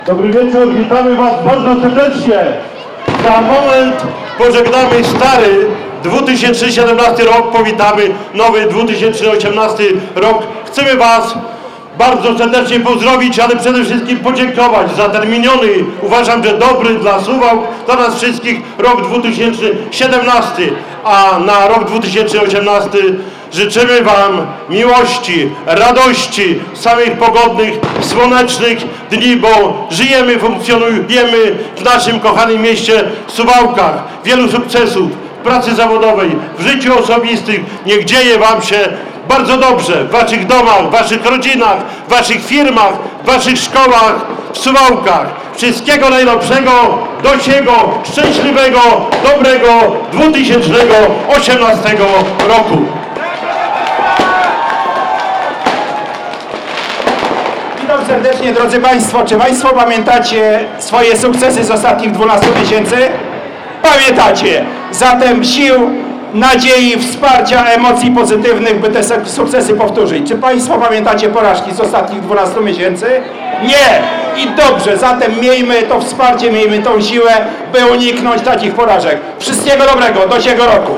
O północy tradycyjnym zwyczajem życzenia zgromadzonej publiczności złożył Czesław Renkiewicz, prezydent Suwałk i Zdzisław Przełomiec, przewodniczący Rady Miejskiej.